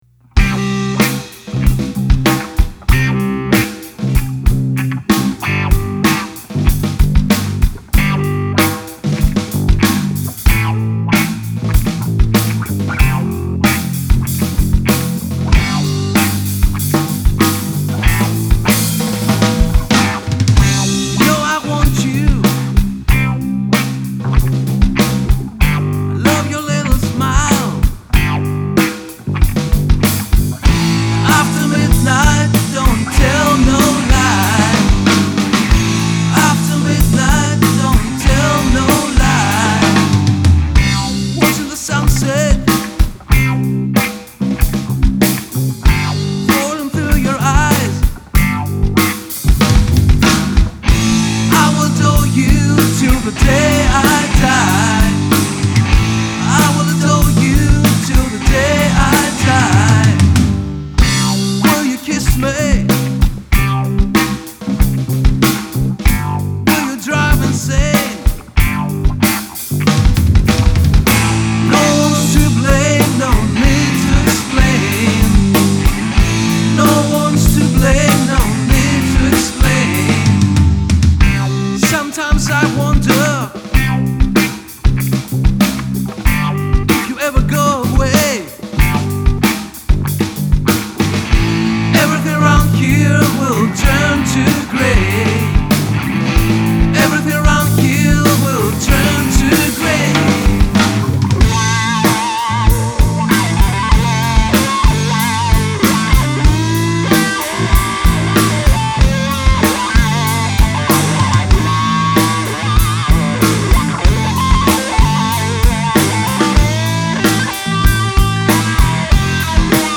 rock n roll
τύμπανα
κιθάρα, φυσαρμόνικα
μπάσο, φωνή